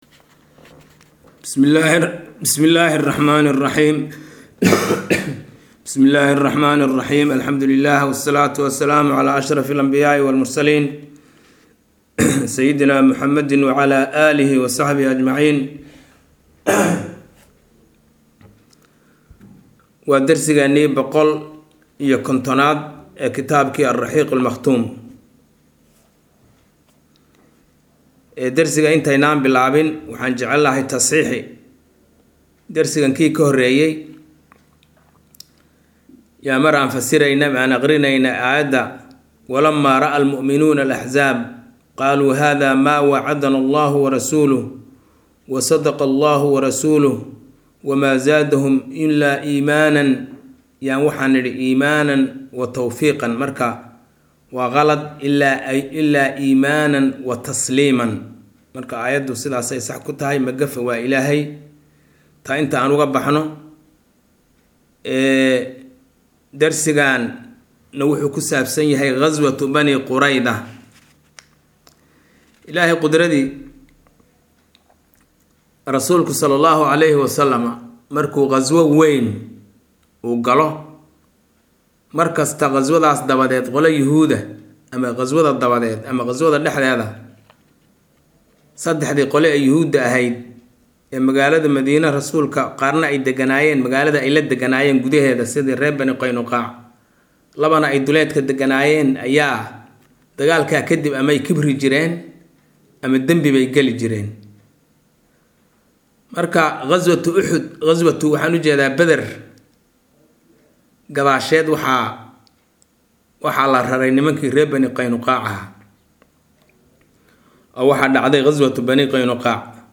Maqal– Raxiiqul Makhtuum – Casharka 150aad